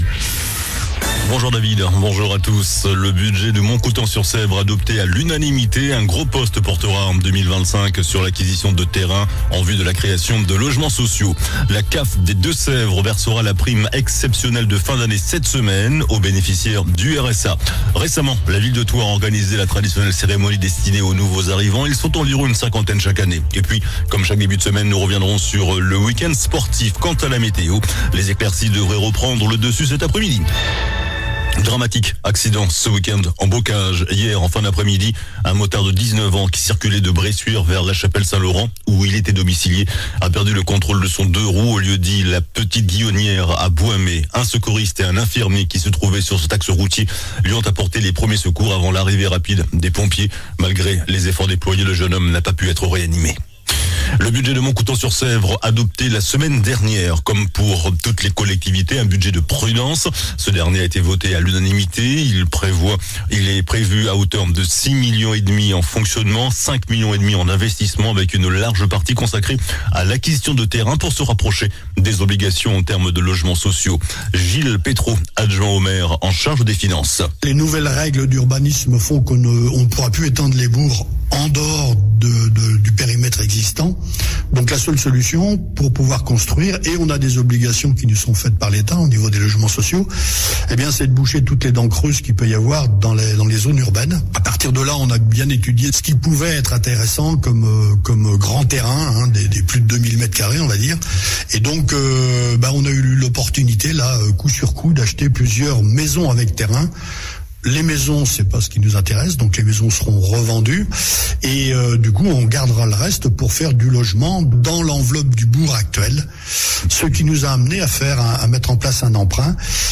JOURNAL DU LUNDI 16 DECEMBRE ( MIDI )